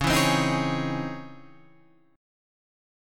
C# Minor Major 9th